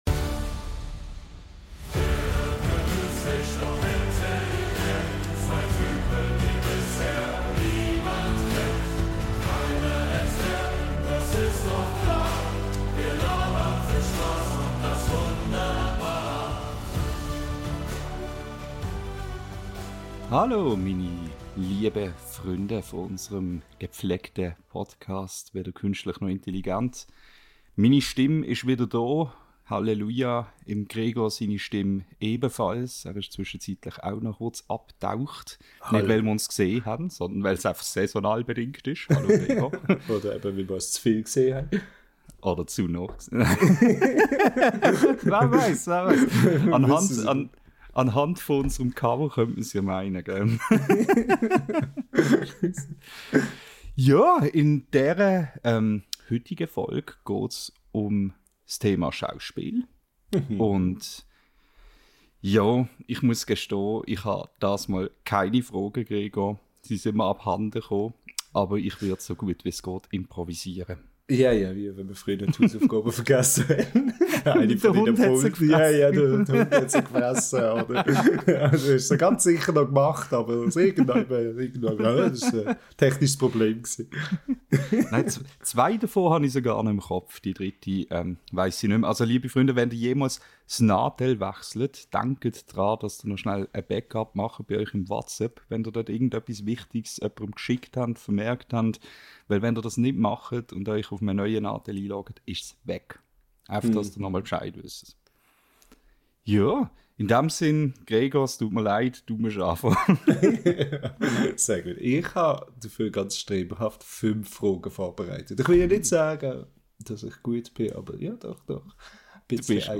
In dieser Folge unseres schweizerdeutschen Podcasts dreht sich alles ums Schauspiel – von grossen Hollywood-Stars bis zu den kleinen Rollen, die wir täglich selbst übernehmen. Wann müssen wir im Alltag Schauspielkunst anwenden?